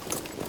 tac_gear_22.ogg